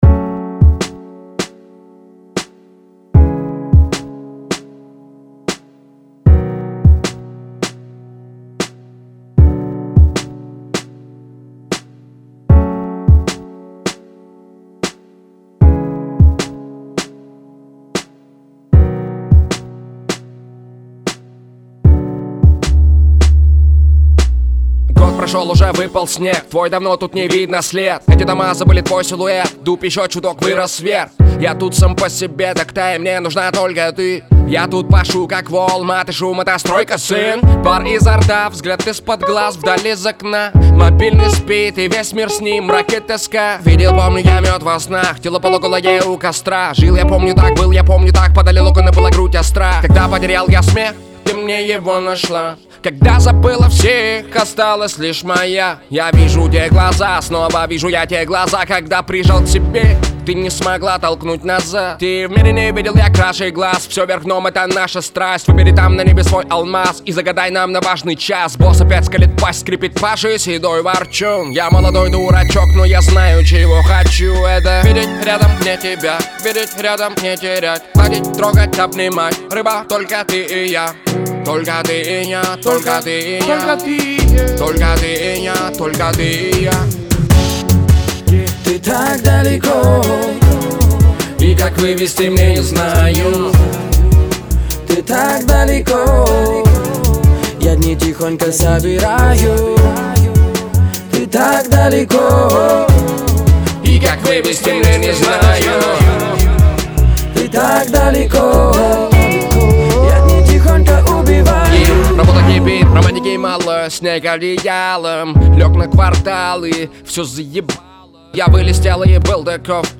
Главная » Файлы » Русский рэп 2016